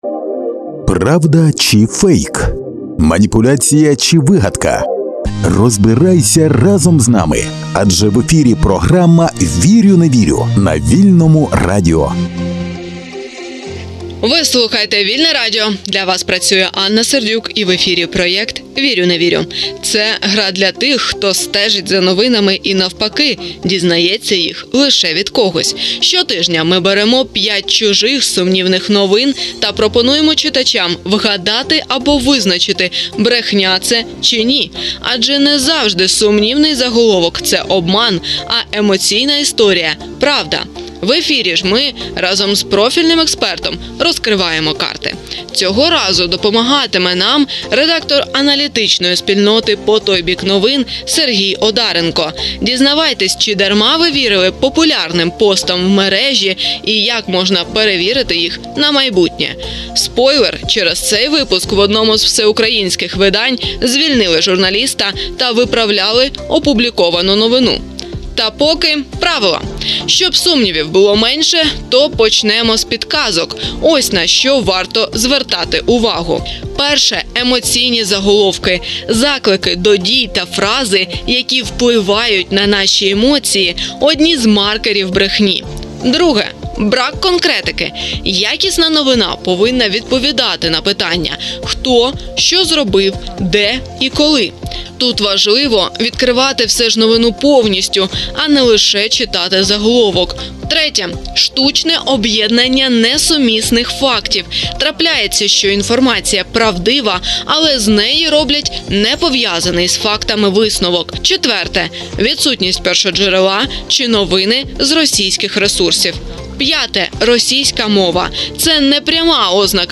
Цього тижня розберемо 5 новин, які ми публікували на наших сторінках з 20 по 24 березня. Які з них правдиві — спробували розпізнати онлайн користувачі соцмереж та випадкові співрозмовники журналістів Вільного радіо на вулицях одного з українських містечок.
Добірку новин та результати опитування ми показали експерту й попросили його винести вердикт по кожній із них, а також прокоментувати сумнівні факти у правдивих новинах і дати інструменти для перевірки інформації.